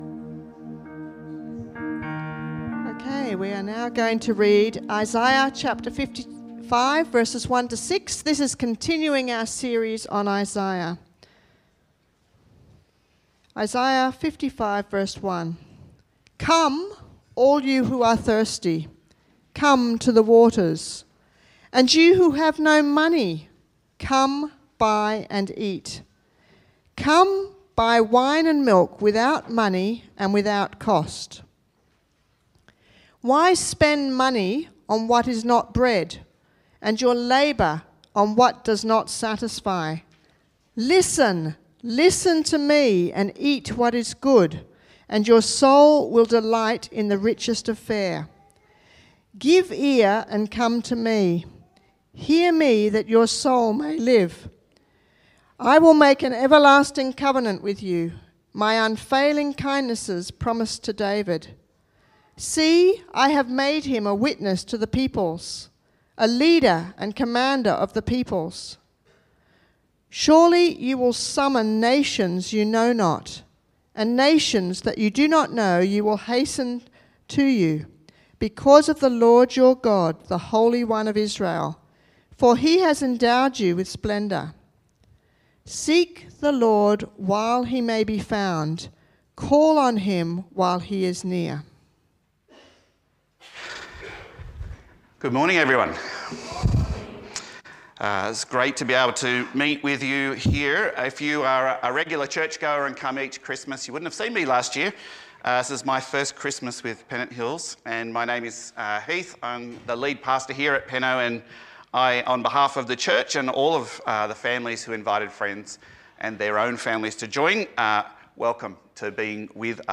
PennoBaps Sermons
Talks from Pennant Hills Baptist